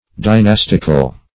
\Dy*nas"tic*al\
dynastical.mp3